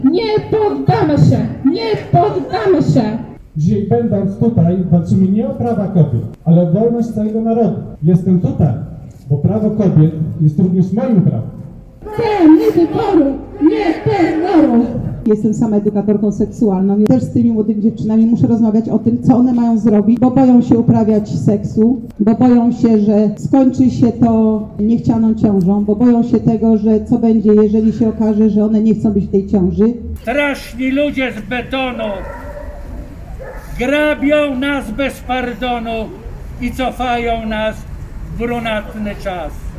Protest aborcyjny w Zielonej Górze
W czwartek około 17:00 przy filharmonii grupa zielonogórzan sprzeciwiała się wyrokowi Trybunału, który orzekł, że przepis ustawy z 1993 r. zezwalający na aborcję w przypadku ciężkiego i nieodwracalnego upośledzenia płodu albo nieuleczalnej choroby zagrażającej jego życiu jest niekonstytucyjny: